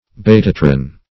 betatron \be"ta*tron\ n.